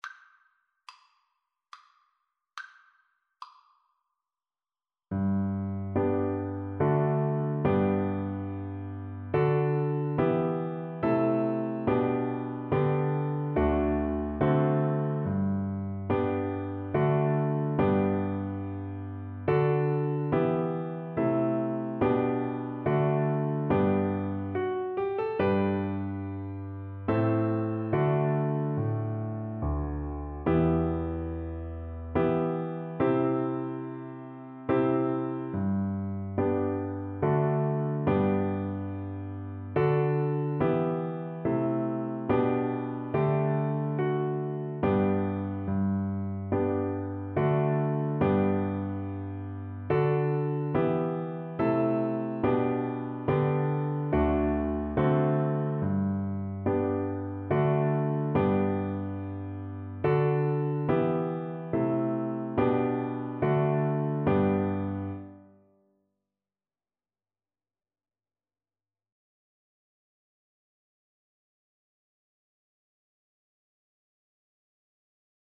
Viola
G major (Sounding Pitch) (View more G major Music for Viola )
3/4 (View more 3/4 Music)
Traditional (View more Traditional Viola Music)